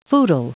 Prononciation du mot footle en anglais (fichier audio)
Prononciation du mot : footle